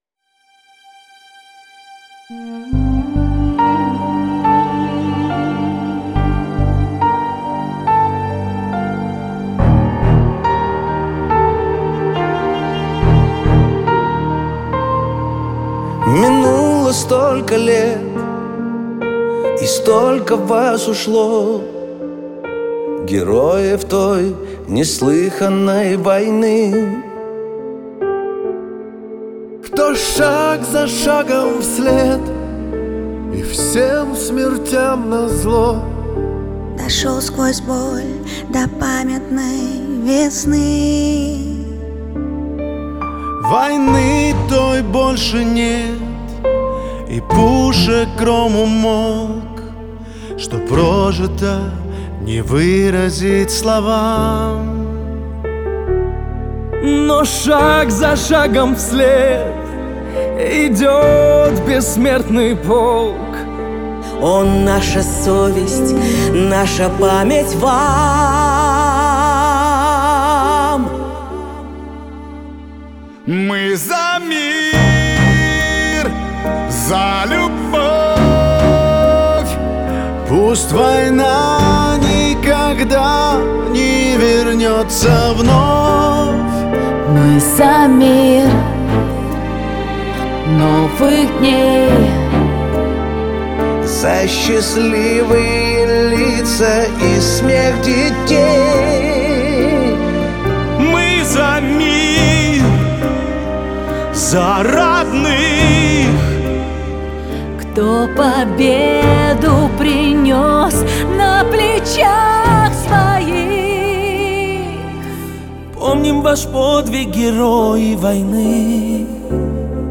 Трек размещён в разделе Русские песни / Эстрада / 2022.